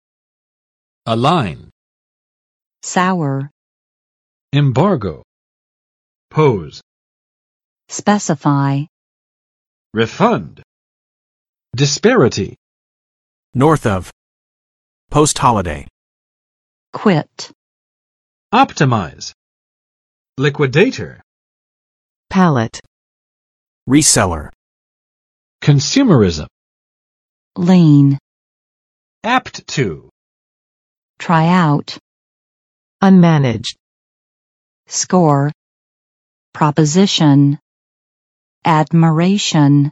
[əˋlaɪn] v. 使结盟；使密切合作